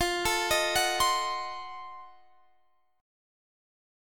Listen to F7b9 strummed